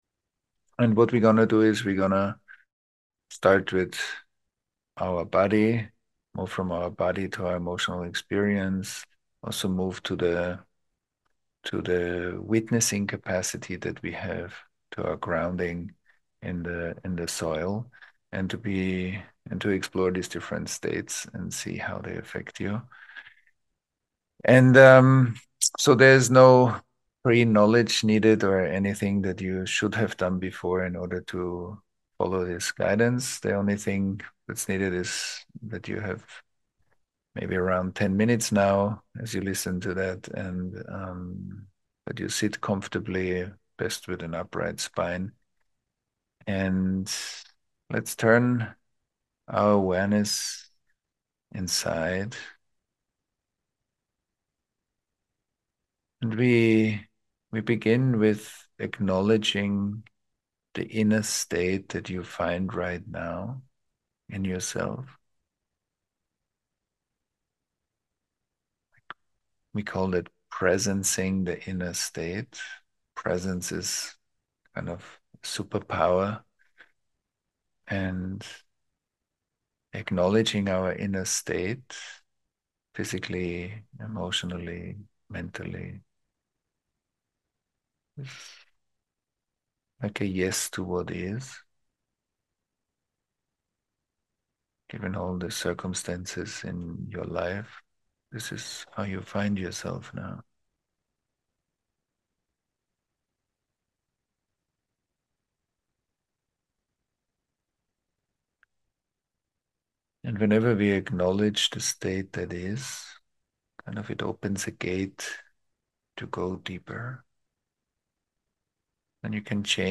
Click here to jump to the guided meditation practice.